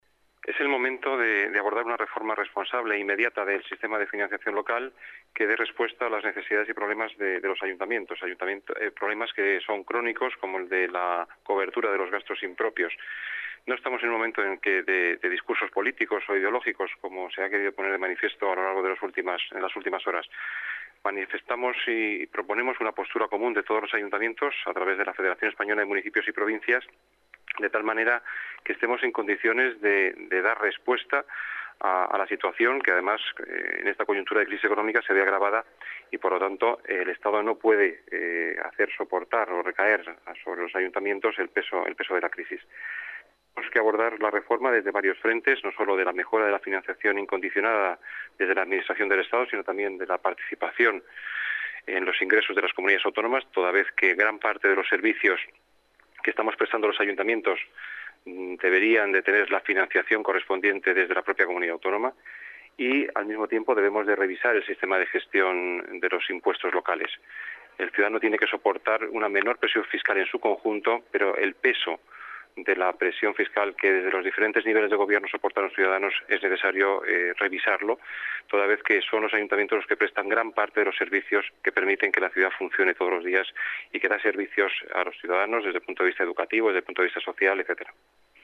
Nueva ventana:Declaraciones del delegado de Hacienda y Administración Pública, Juan Bravo